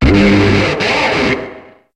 Cri de Blizzaroi dans Pokémon HOME.